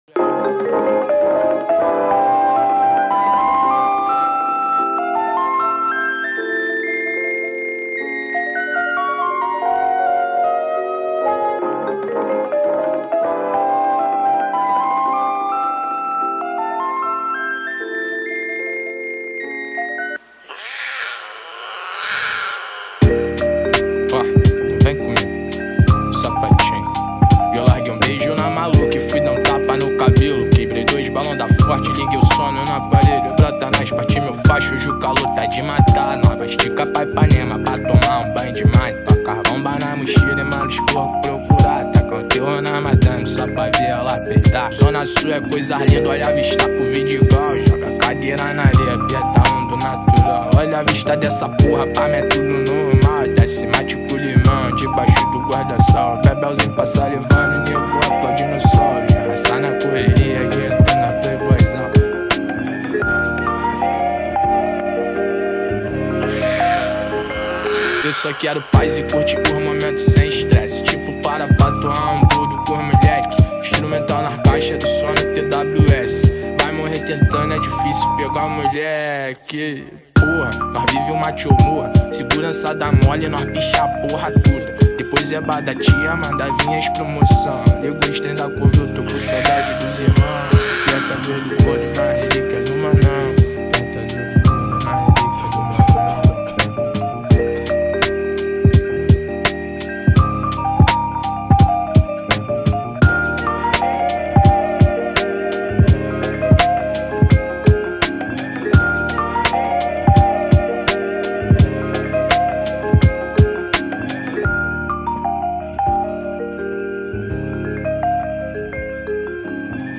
2024-06-09 00:10:33 Gênero: Rap Views